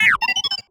sci-fi_driod_robot_emote_26.wav